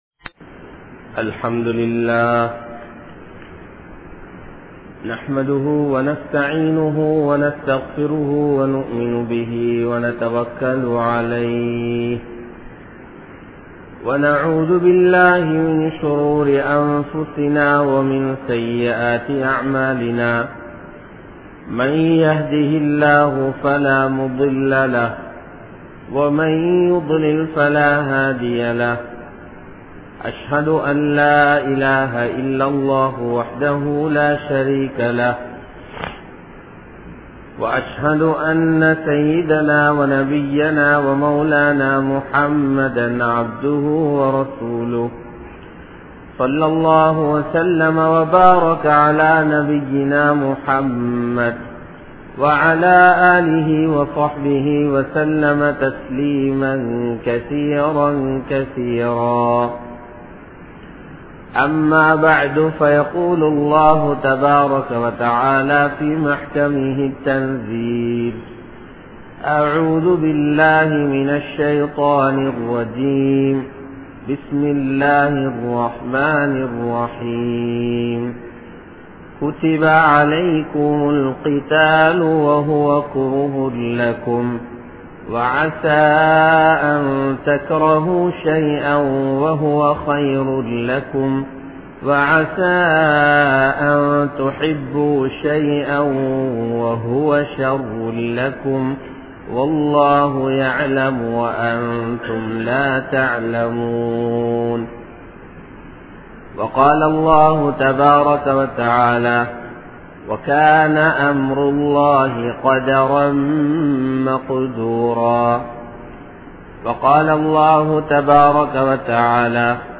Maranaththai Payap Padaatheerhal (மரணத்தை கண்டு பயப்படாதீர்கள்) | Audio Bayans | All Ceylon Muslim Youth Community | Addalaichenai